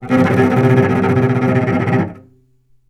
vc_trm-C3-mf.aif